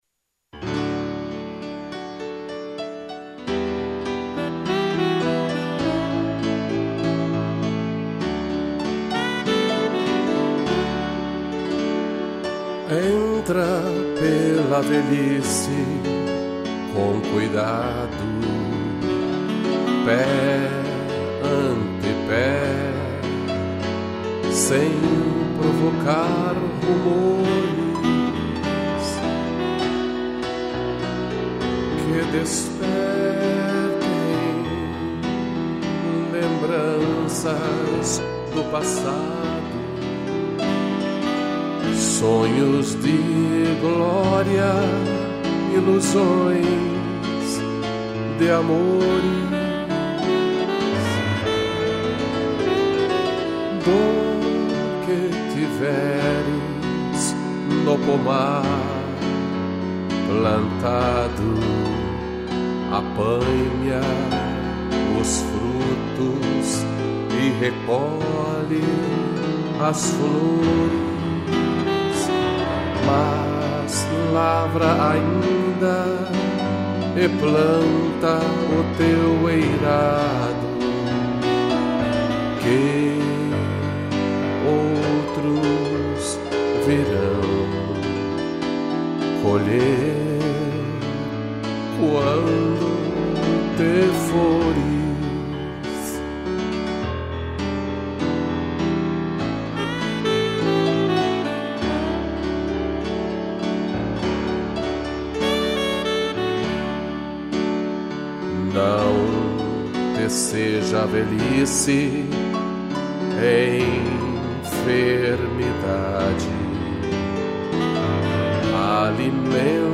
voz
2 pianos e sax